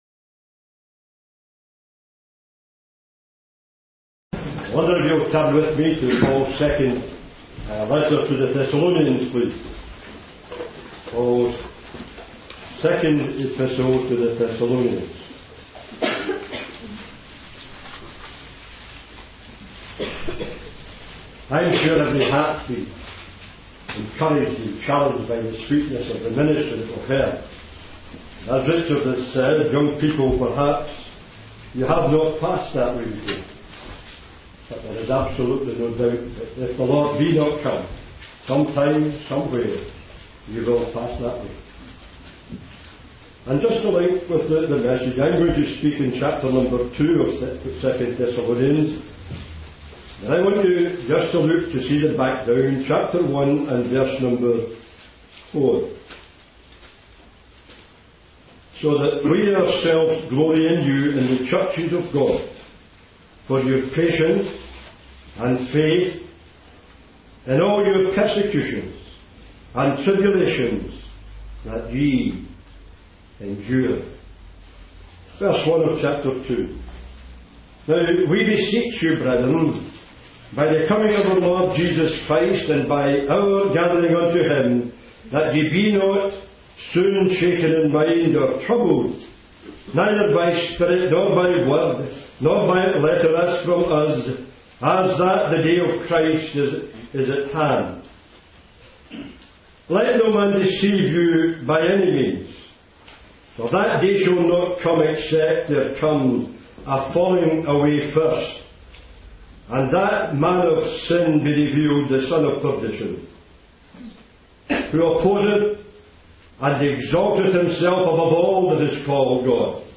In this sermon, the preacher emphasizes the importance of the gospel and its impact on believers.